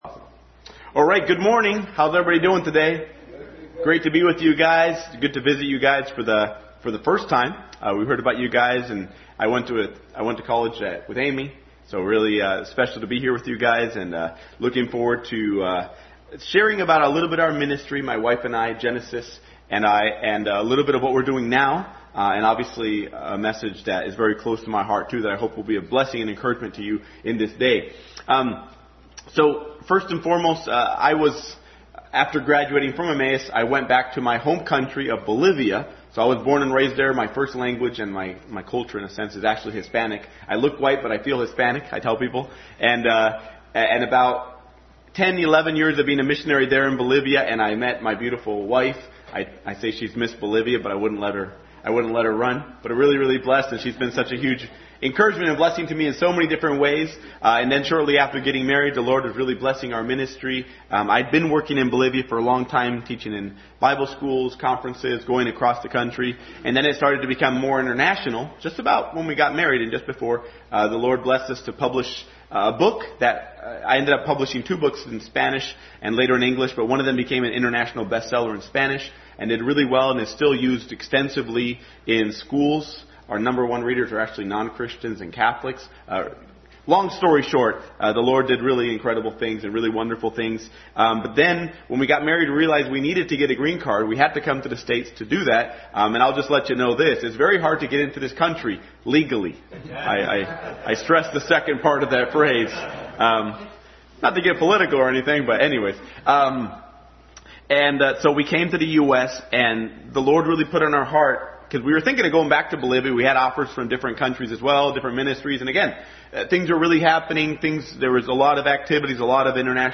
Family Bible Hour Message.